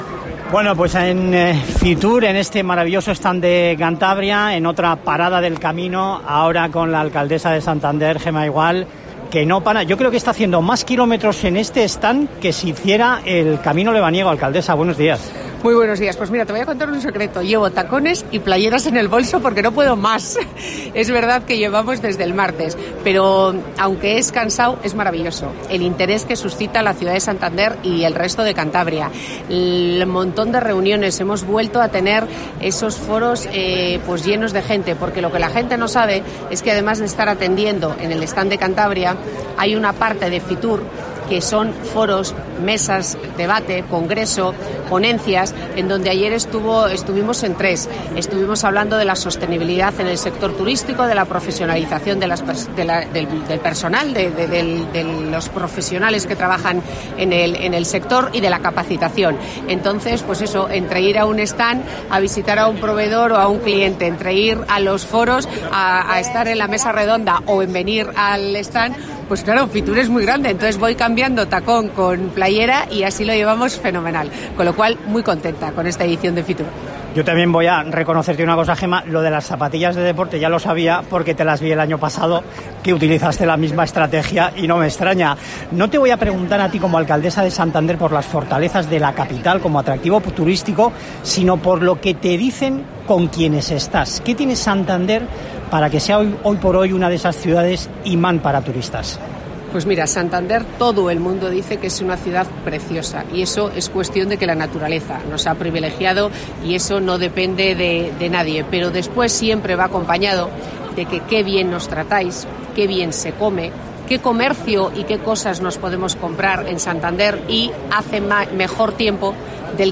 La edición de Fitur que acaba de cerrar sus puertas ha sido todo un éxito para Santander, según ha contado en COPE Cantabria su alcaldesa Gema Igual.